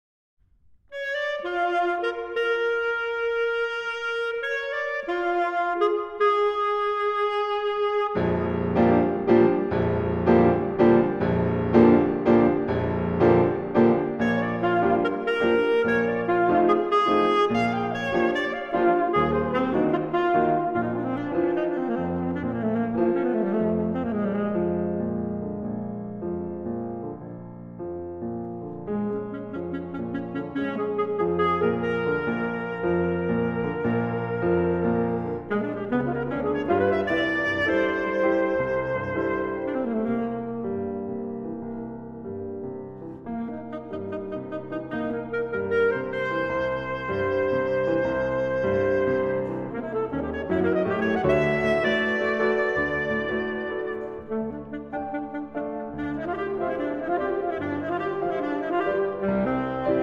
Saxophone
Piano